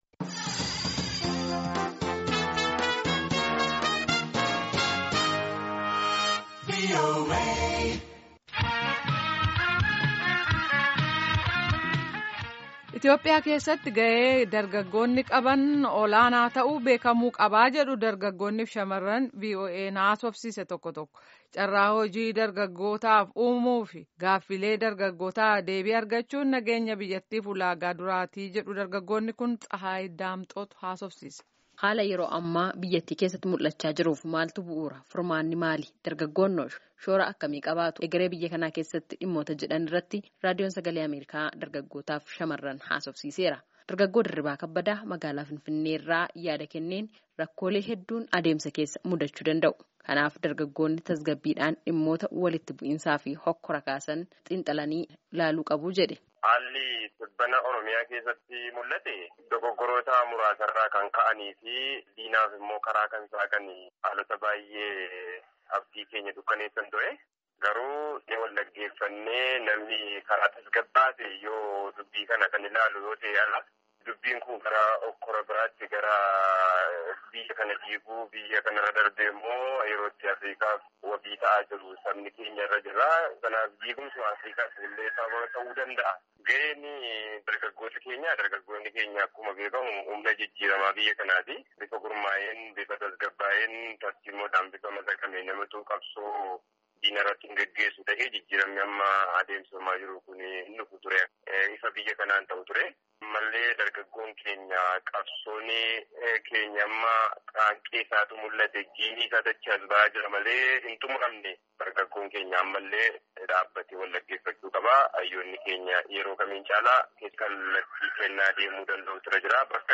Dargaggoonni fi Shaamaran Raadiyoon Sagalee Ameerikaa haasofsiise Itiyoophiyaa keessatti gaheen dargaggootaa ol’aanaa ta'uun isaa beekamuu akka qabu hubachiisan.